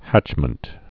(hăchmənt)